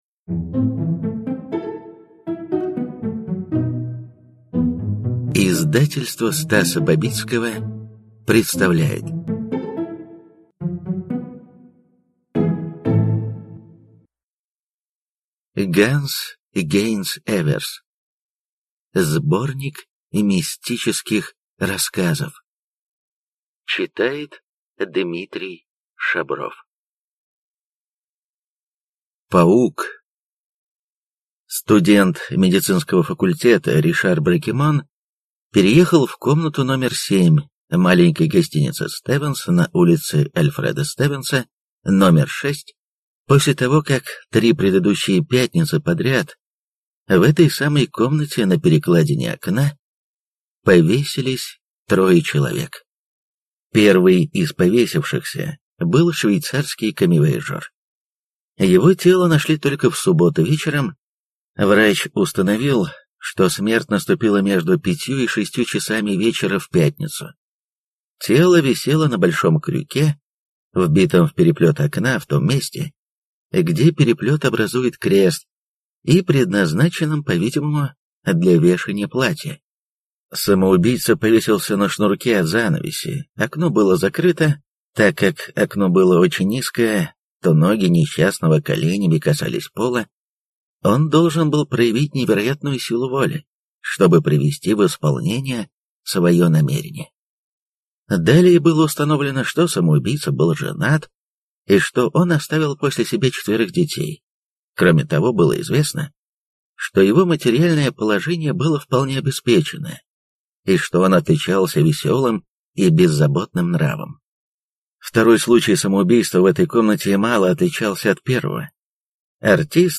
Аудиокнига Шкатулка для игральных марок | Библиотека аудиокниг